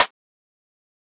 SnareRim Groovin 2.wav